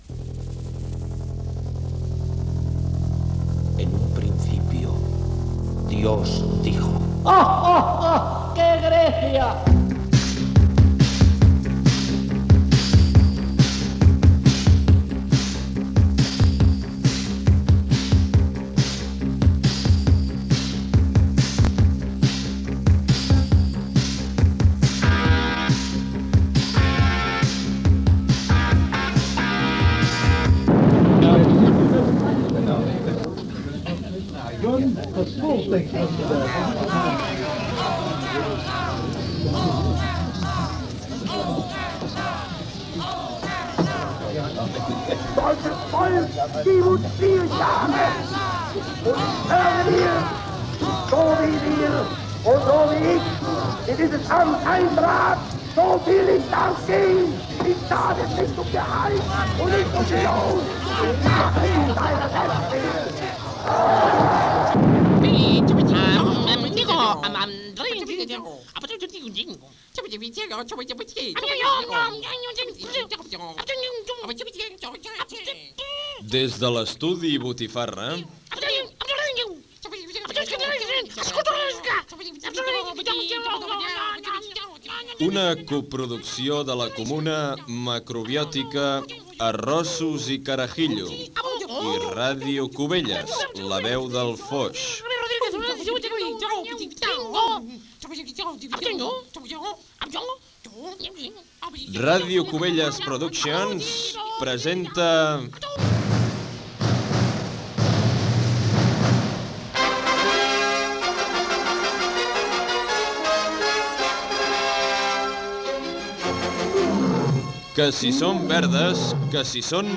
Careta del programa, presentació del programa
Gènere radiofònic Entreteniment